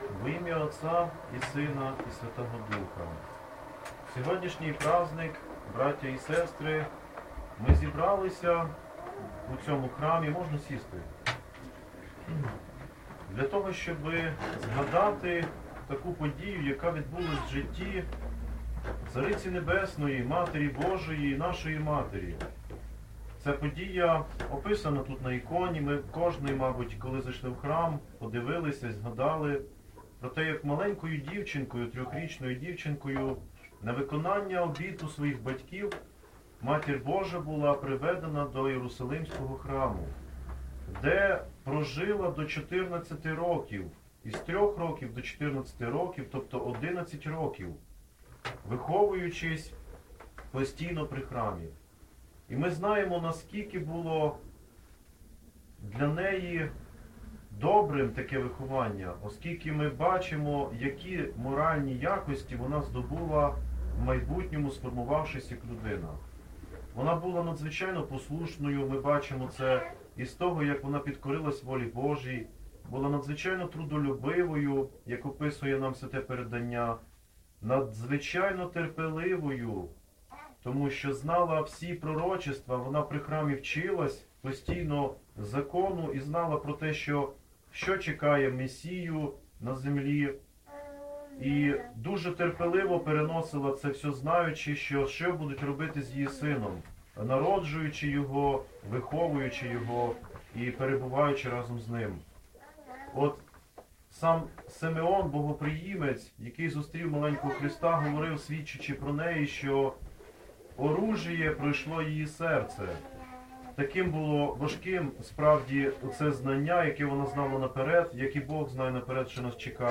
Проповідь у день Введення в храм Пресвятої Богородиці – Храм Святителя Іоанна Шанхайського і Сан-Франциського м. Ужгорода